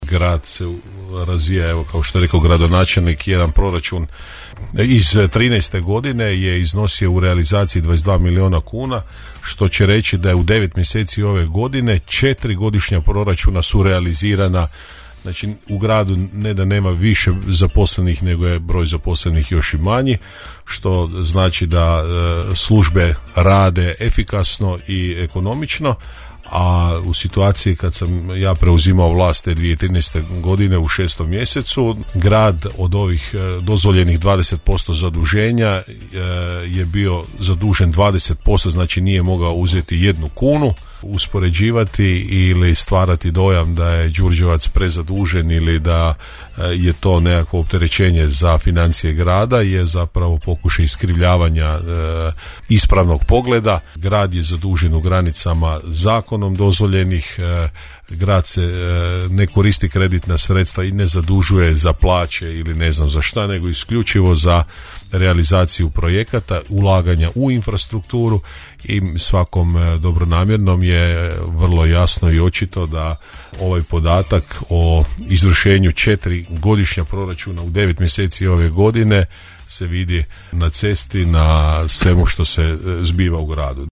U Gradskim temama Podravskog radija gostovali su gradonačelnik Grada Đurđevca Hrvoje Janči i predsjednik Gradskog vijeća Grada Đurđevca Željko Lackovi